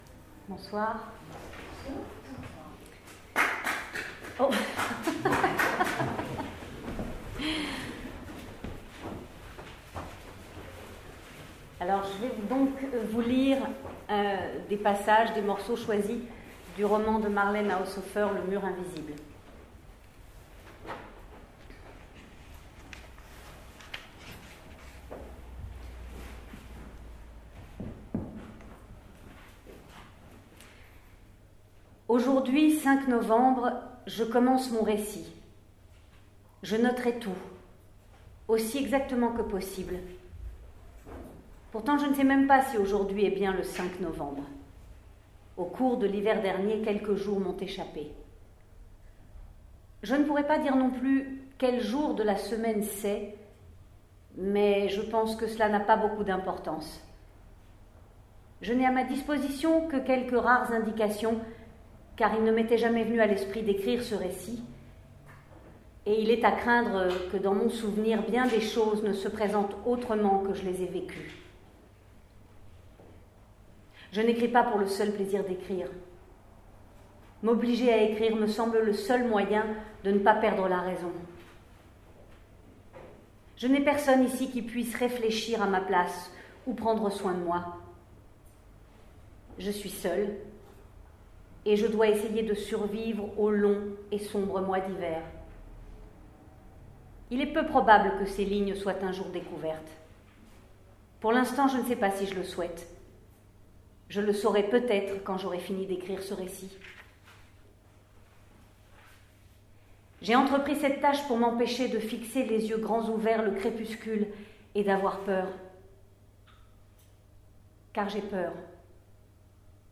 Création mercredi 5 février à 19h30 au Musée de la Chasse et de la Nature.